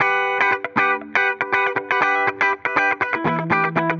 Index of /musicradar/dusty-funk-samples/Guitar/120bpm
DF_BPupTele_120-E.wav